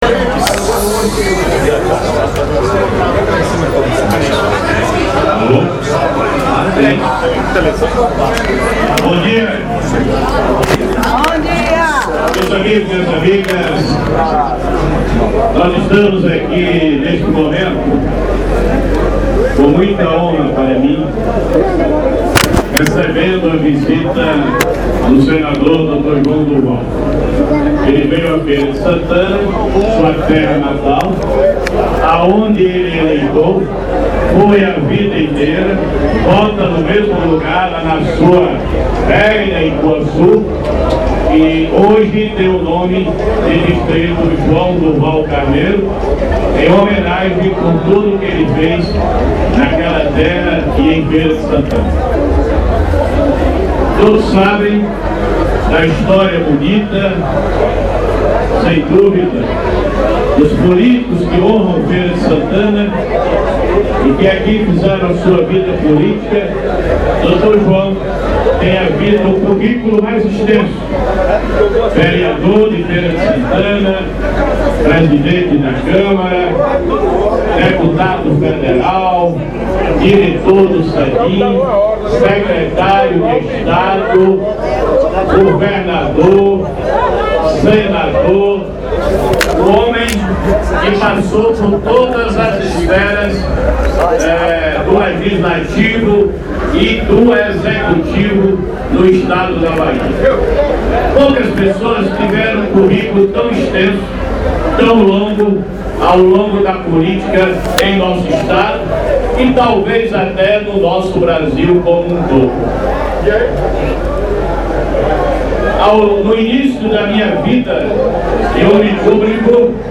Visivelmente emocionado e quase às lagrimas, Ronaldo agradeceu a visita e o gesto de Durval, lembrando que iniciou a carreira política com o apoio do então prefeito João Durval.
|titles=Discurso de José Ronaldo sobre apoio de João Durval] [audio:
Discurso-de-Jose-Ronaldo-sobre-apoio-de-Joao-Durval.mp3